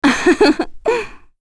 Epis-Vox-Laugh1.wav